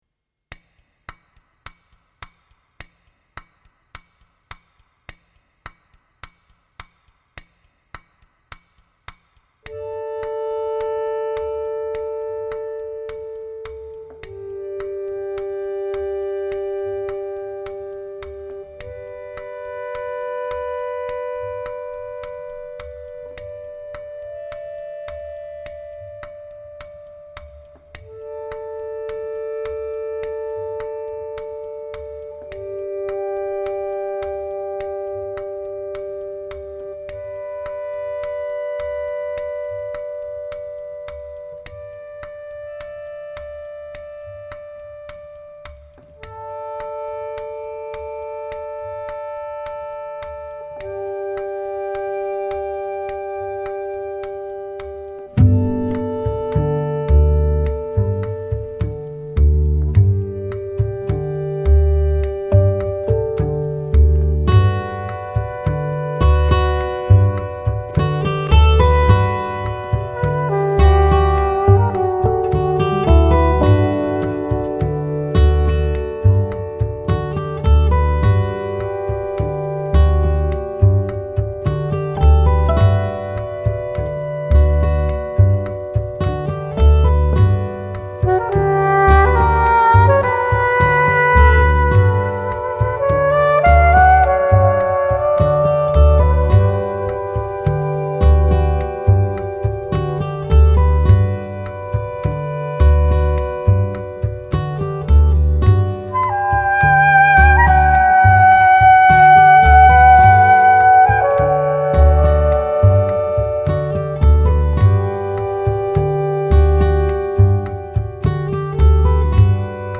薩克斯風、單簧管及其它樂器
吉他及其它樂器
手風琴、鋼琴及其它樂器
貝斯、打擊樂器及其它樂器